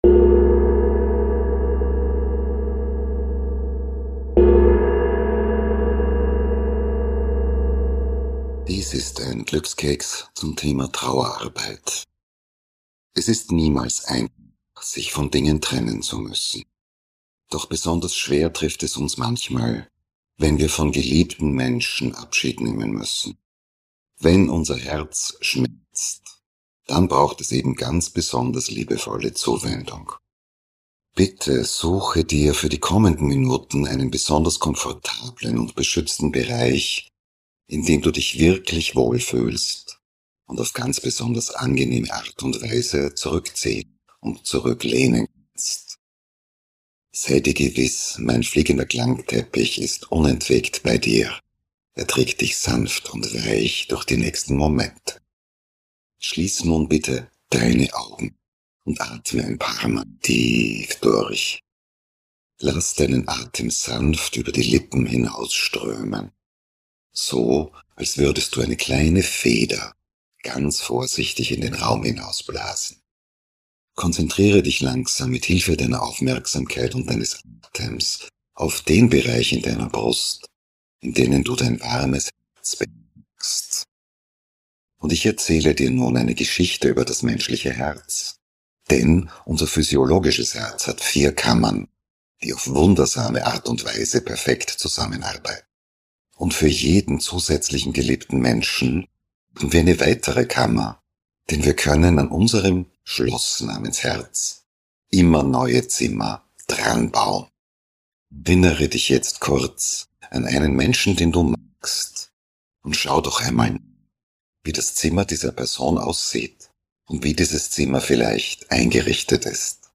Ein sanfte Meditation, um Schmerz in Frieden zu verwandeln.
Meditation für Herz, Körper und Seele – sanft, tief und tröstend.